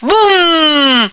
flak_m/sounds/female1/est/F1boom.ogg at 098bc1613e970468fc792e3520a46848f7adde96